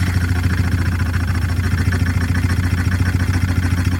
Motorboat Engine
An outboard motorboat engine rumbling at cruising speed with splashing wake
motorboat-engine.mp3